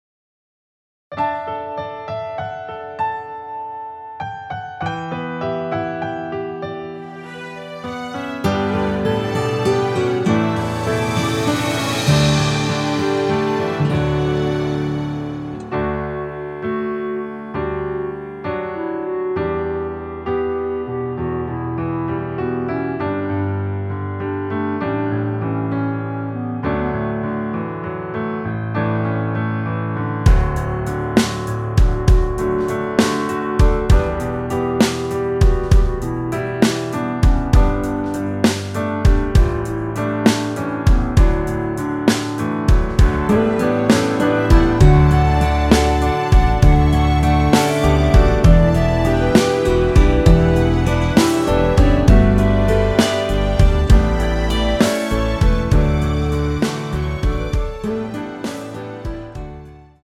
원키에서(-6)내린 멜로디 포함된 짧은 편곡 MR입니다.
앞부분30초, 뒷부분30초씩 편집해서 올려 드리고 있습니다.
중간에 음이 끈어지고 다시 나오는 이유는